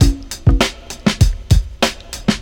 • 132 Bpm Drum Beat G Key.wav
Free breakbeat sample - kick tuned to the G note. Loudest frequency: 1020Hz
132-bpm-drum-beat-g-key-AGB.wav